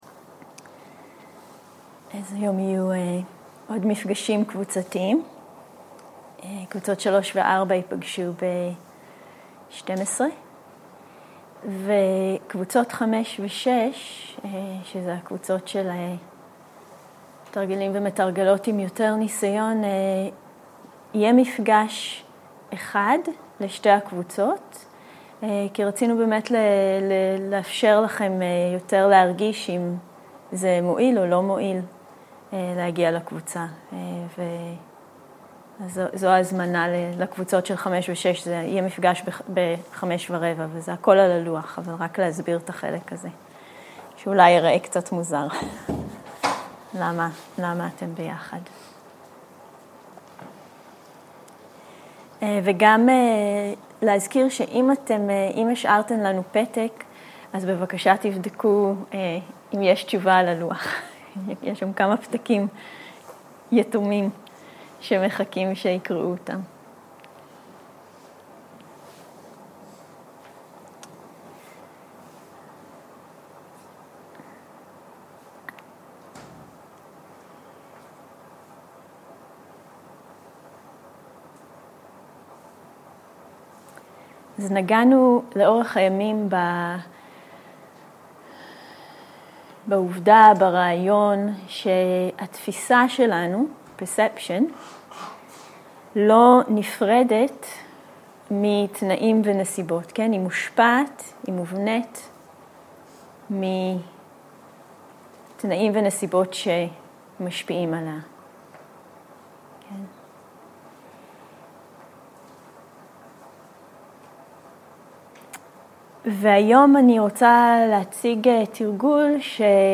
שיחת הנחיות למדיטציה שפת ההקלטה: עברית איכות ההקלטה: איכות גבוהה מידע נוסף אודות ההקלטה